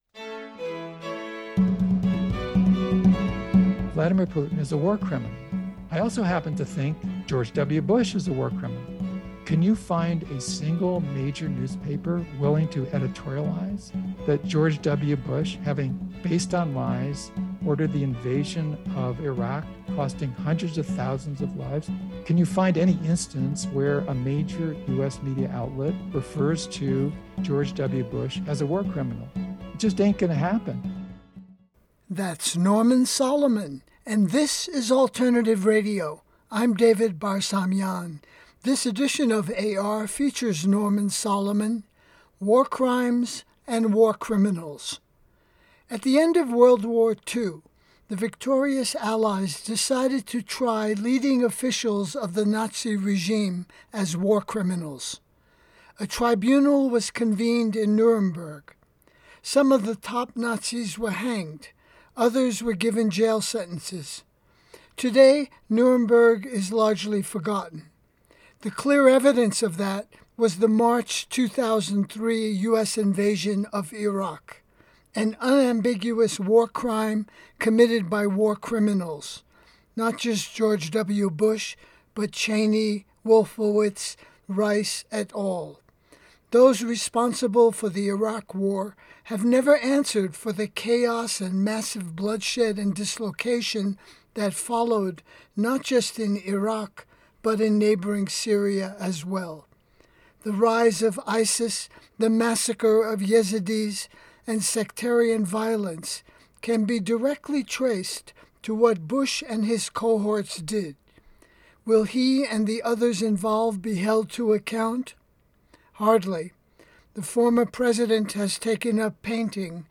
File Information Listen (h:mm:ss) 0:57:00 Norman Solomon War Crimes & War Criminals Download (0) NormanSolomon-WarCrimes_pacNCRA_1.mp3 54,742k 128kbps Mono Comments: Point Reyes Station, CA Listen All